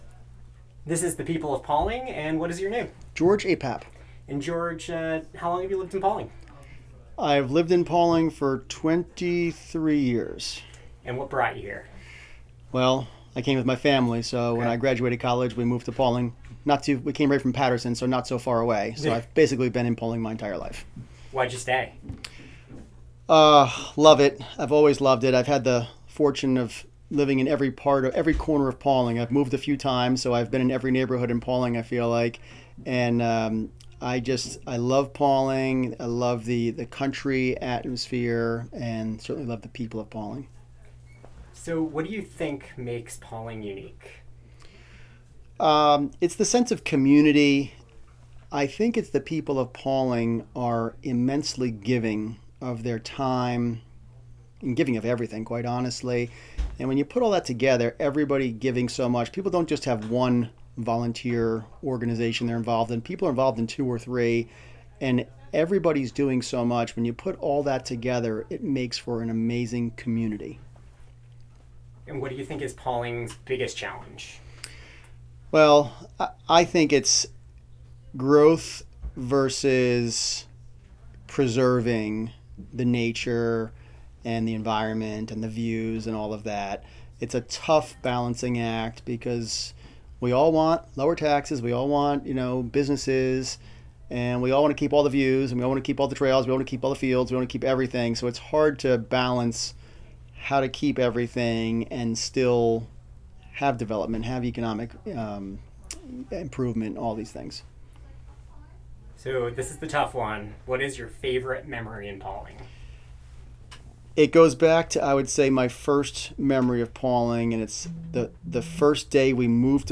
The conversation was recorded as part of the People of Pawling Project. The project saught to create brief snapshots of Pawling that could be easily consumed and show the richness of the community during the time that the recordings were done.